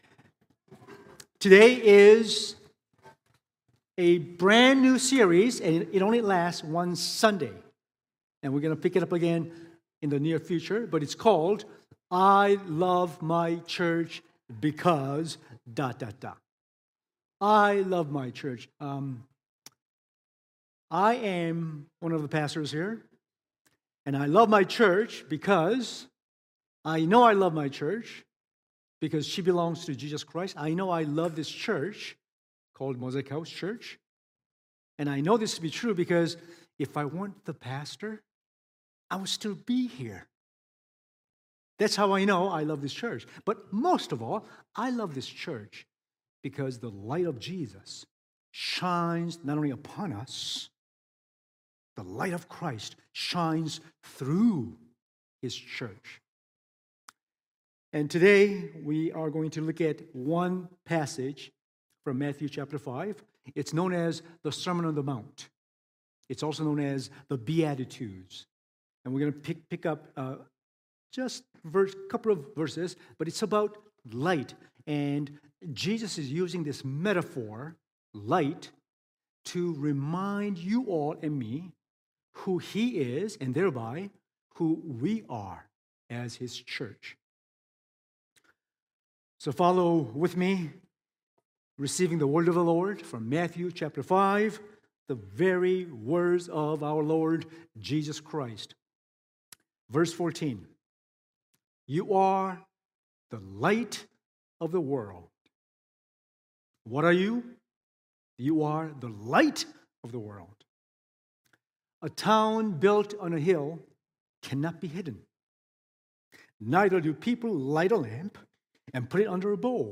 Sermons | mosaicHouse